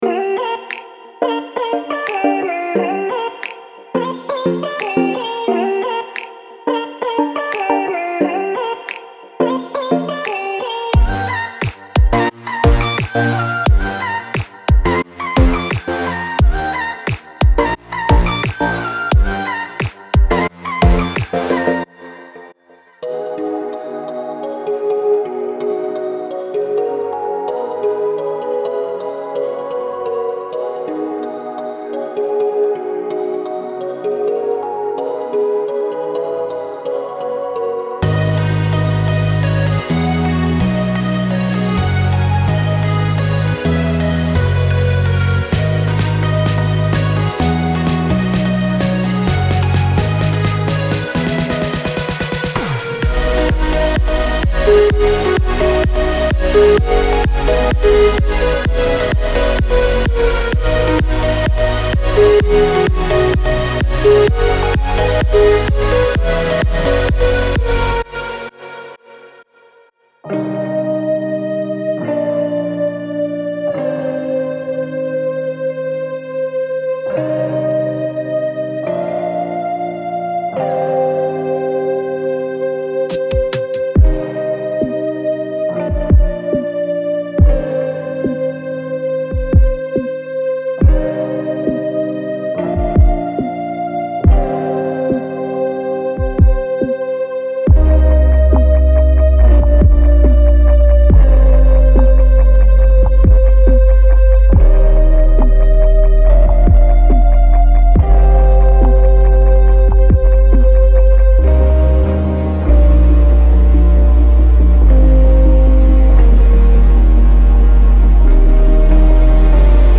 从巨大的，令人头疼的和弦到制作精美的主音，强劲的低音音色等等，这些预置为您的曲目营造了基调。
这些功能强大但令人动情的MIDI循环涵盖了从Future Bass，Pop到House的所有流派和情绪。